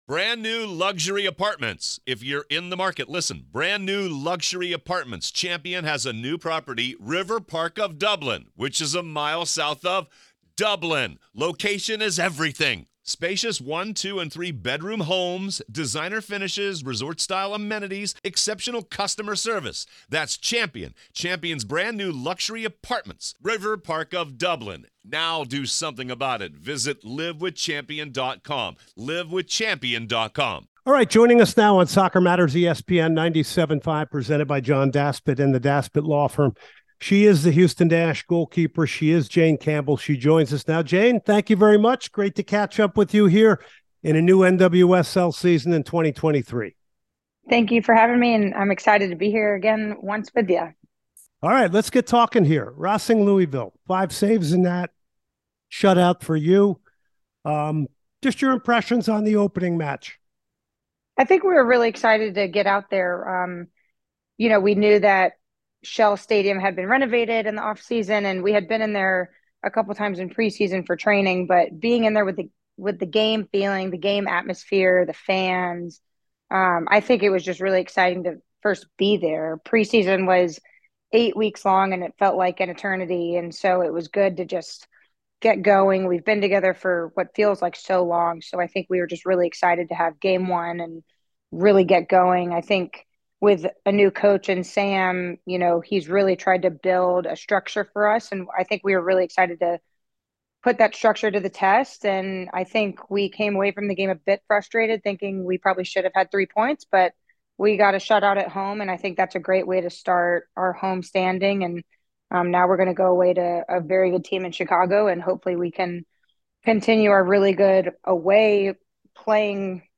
The Houston start off their season with a home opener against Racing Louisville. Ending the game with a clean sheet, Goalkeeper Jane Campbell joins the show for an interview about the game, & much more.